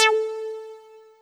synTTE55010shortsyn-A.wav